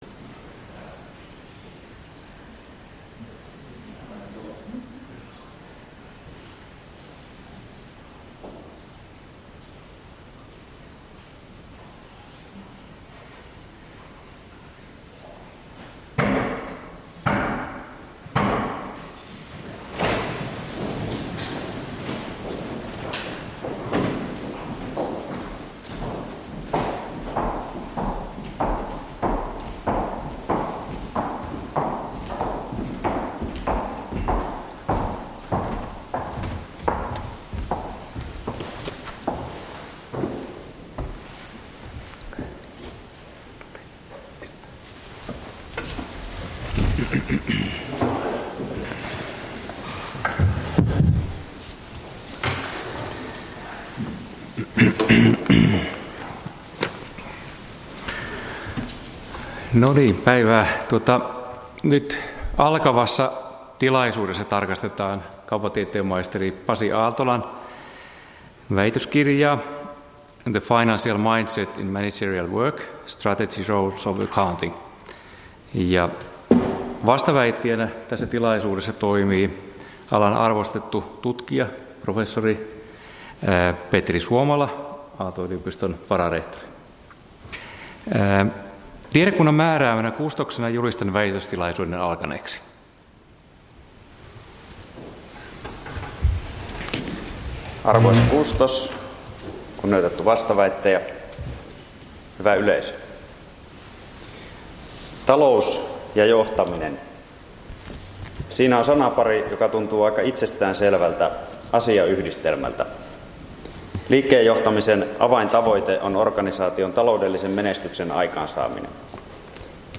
väitöstilaisuus